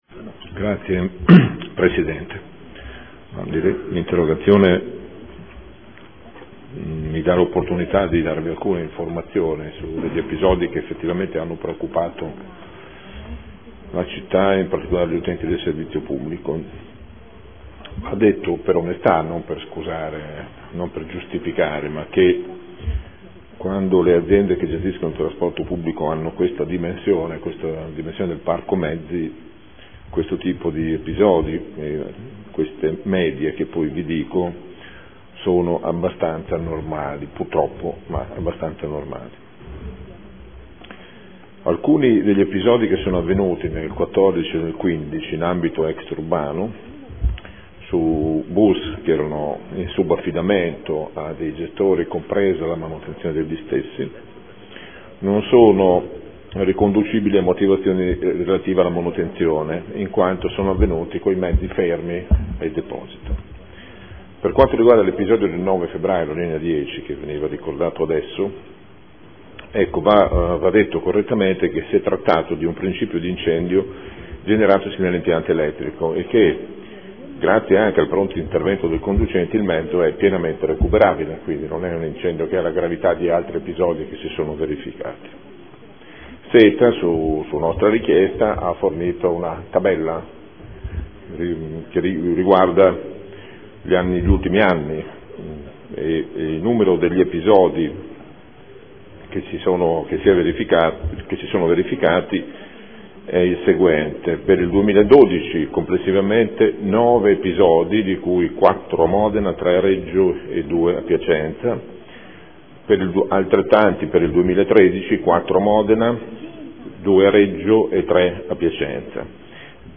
Gabriele Giacobazzi — Sito Audio Consiglio Comunale